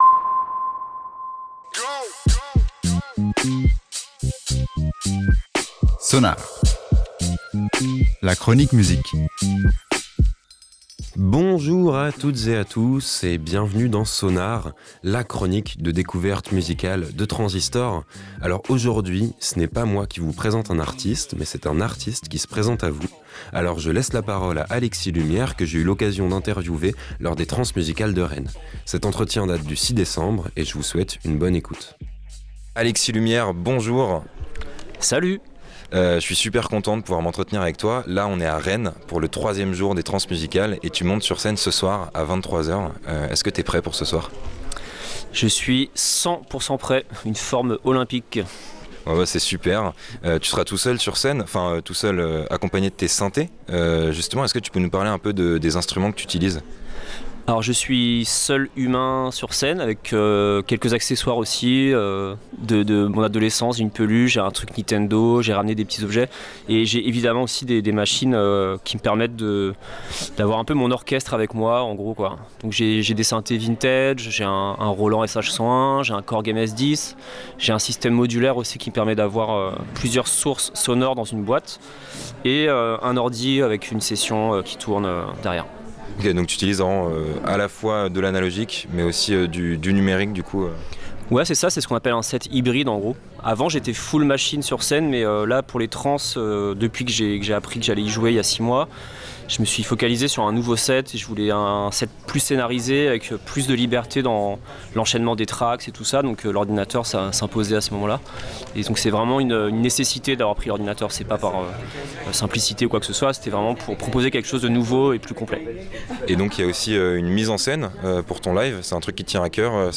Aujourd'hui dans SONAR, on revient sur notre rencontre
SONAR (Trans Musicales) - Interview